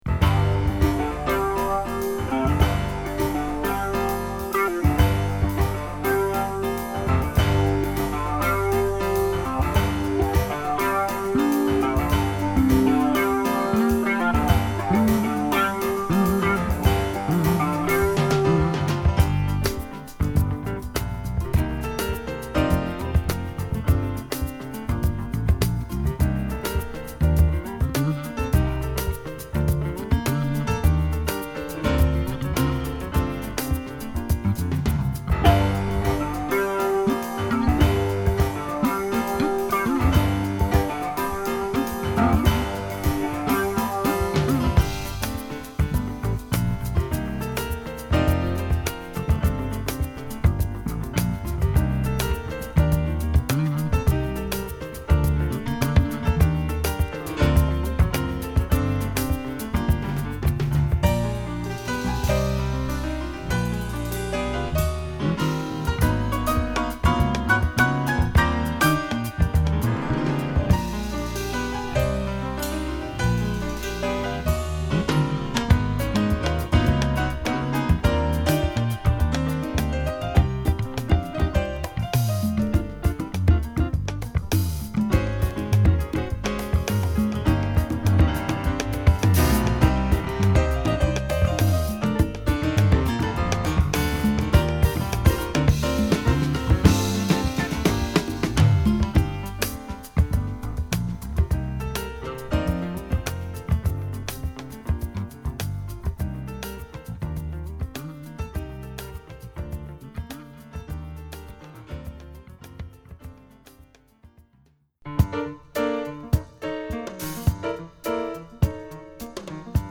ハーフスピードマスタードの高音質盤